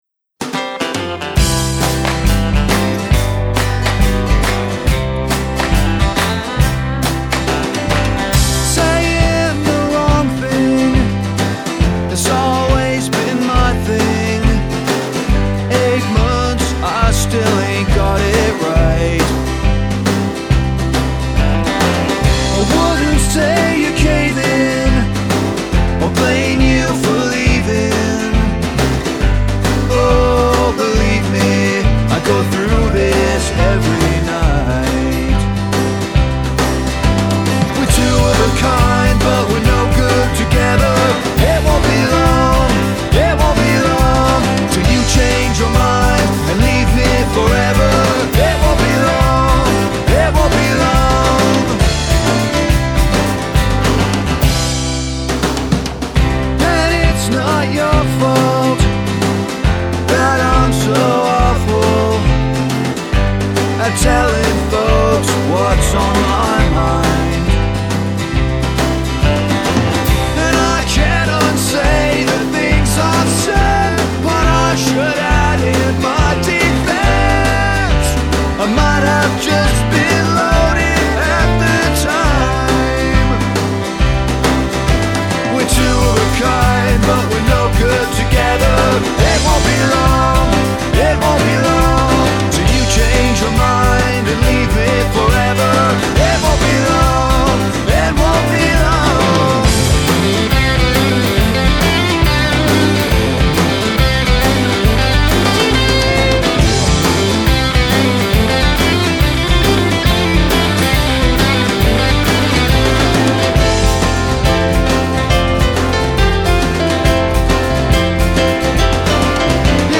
Really nice guitar solo takes this up a notch.
love the country feel of the intro.
love the lead break into the piano.
The Handclaps were: Non-obtrusive.